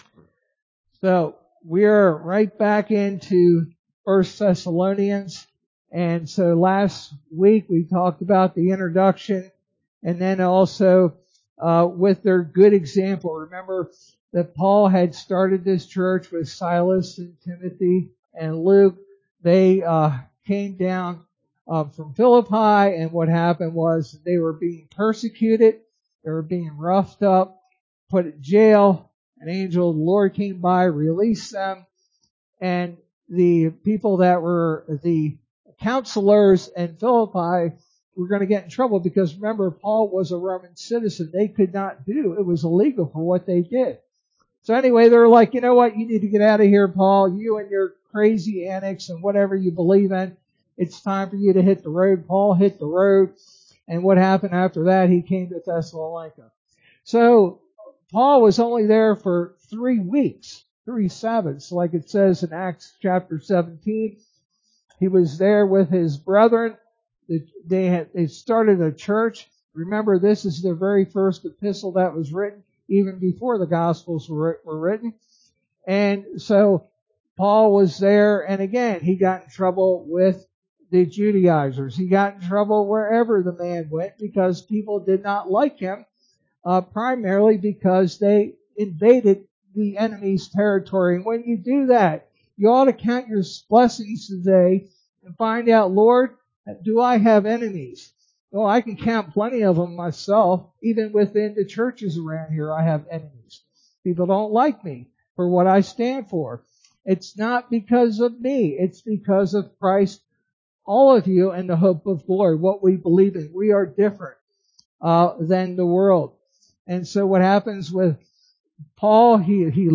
Sermon verse: 1 Thessalonians 2:1-12